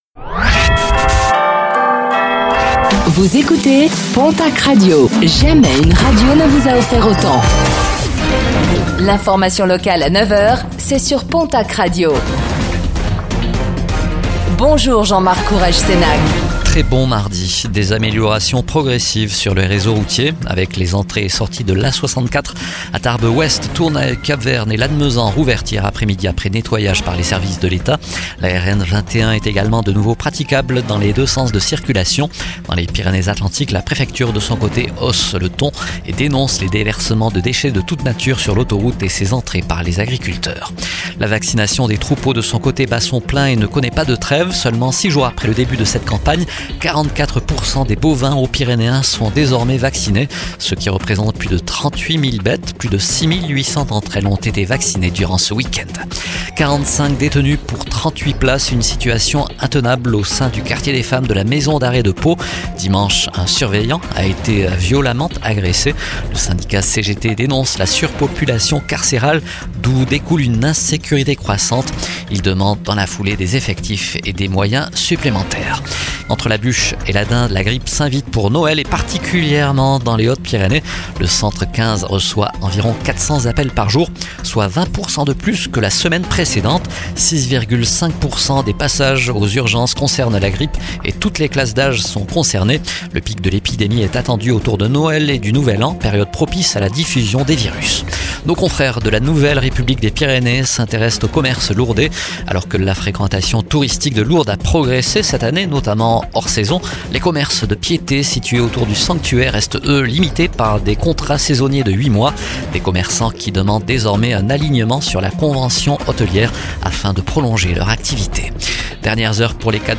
Infos | Mardi 23 décembre 2025